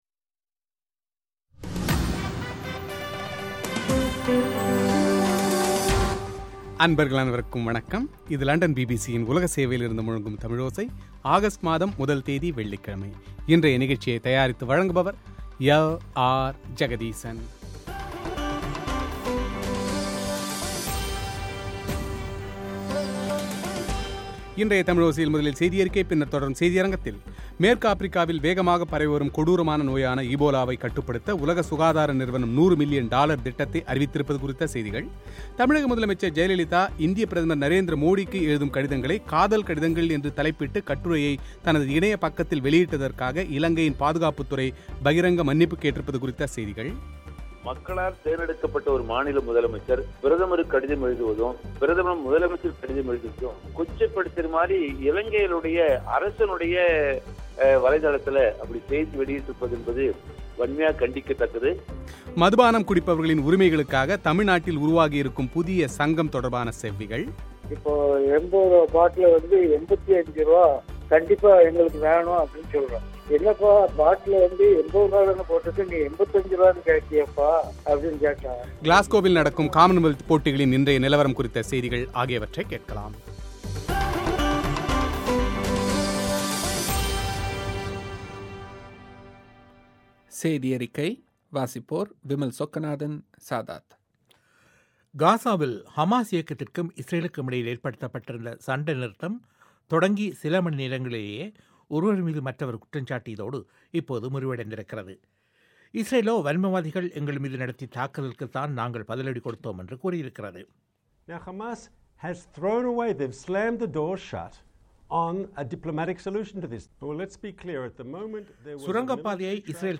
மதுபானம் குடிப்பவர்களின் உரிமைகளுக்காக தமிழ்நாட்டில் உருவாகியிருக்கும் புதிய சங்கம் தொடர்பான செவ்விகள்;